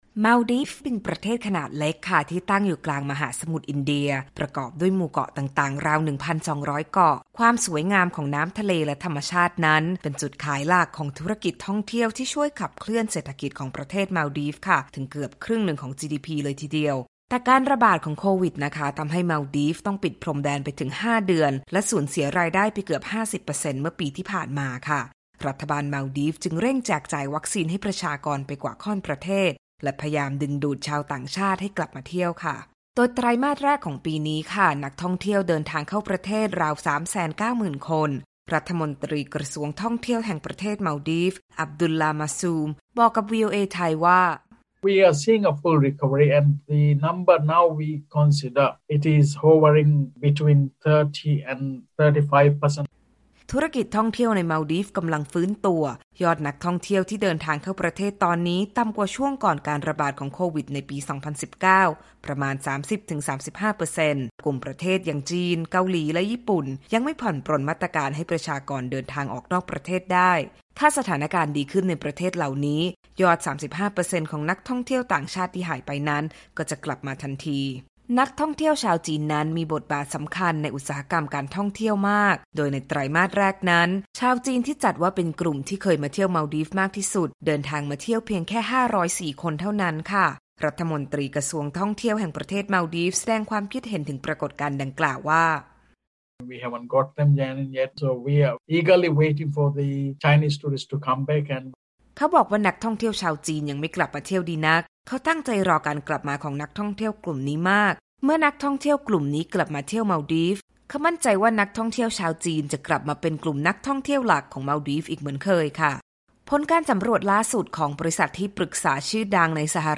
วีโอเอไทยมีโอกาสสัมภาษณ์พิเศษกับรัฐมนตรีกระทรวงท่องเที่ยวของมัลดีฟส์ ถึงยอดนักท่องเที่ยวที่เริ่มทยอยเข้าประเทศ พร้อมทั้งพูดคุยกับองค์กรสมาคมส่งเสริมการท่องเที่ยวภูมิภาคเอเชียแปซิฟิก หรือ PATA ถึงการเปิดประเทศเพื่อดึงดูดชาวต่างชาติ โดยเฉพาะกลุ่มทัวร์จากประเทศจีน